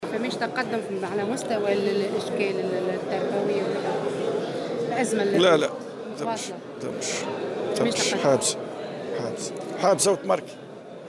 وأضاف في تصريح اليوم لمراسلة "الجوهرة أف أم" على هامش انعقاد الجامعة العامة للكهرباء و الغاز بالحمامات، أن هذا الملف لا يزال يراوح مكانه وأنه لا وجود لأي مستجدات تذكر.